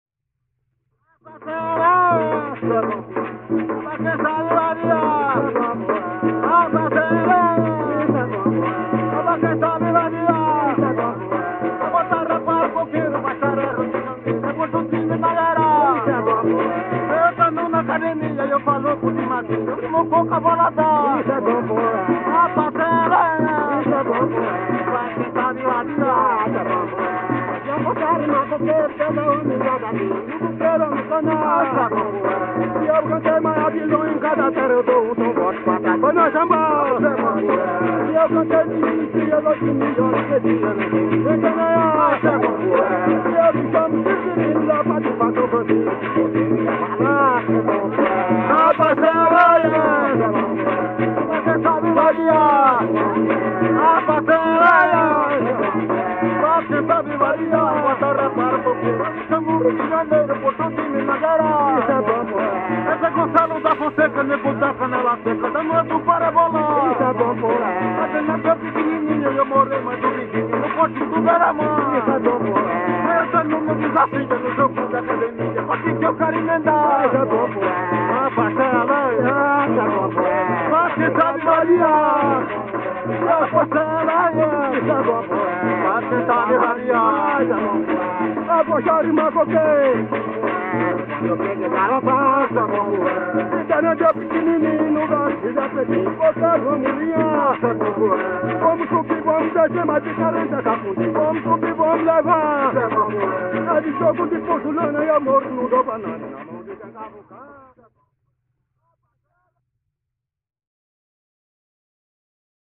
Coco martelo